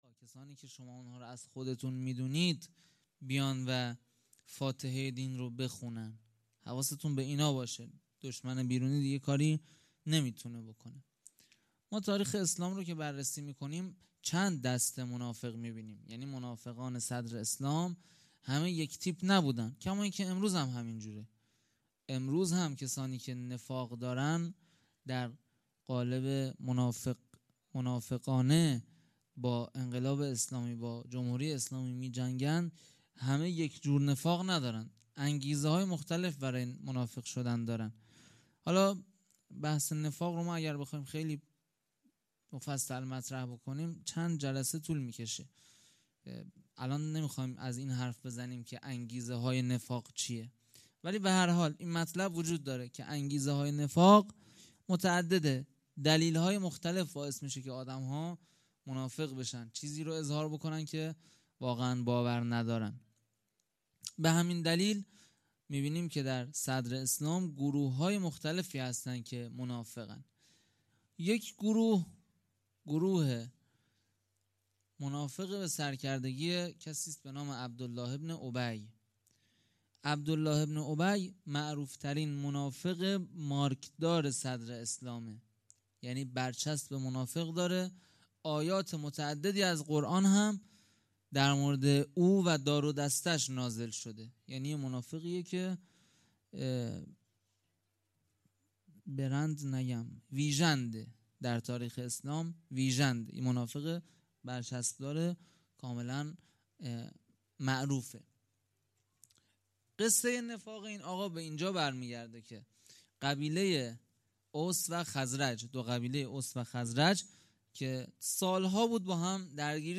سخنرانی
هیئت حسن جان اهواز